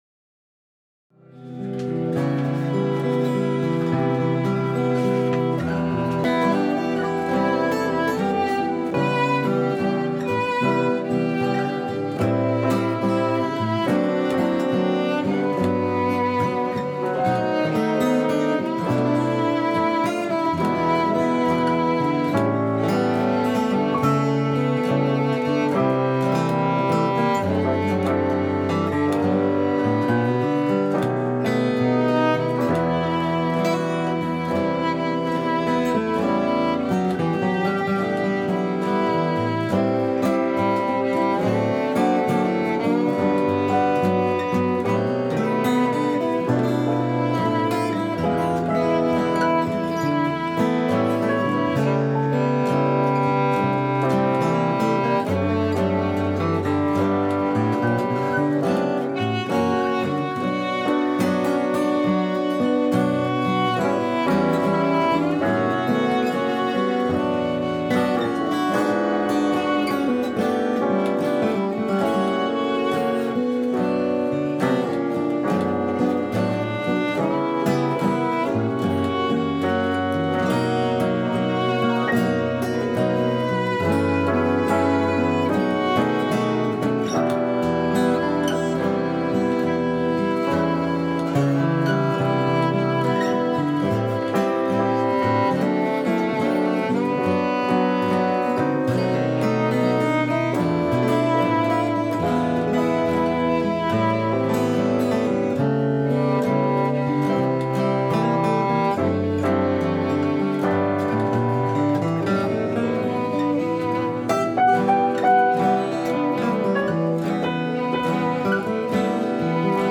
jazz standards ragtime American songbook Latin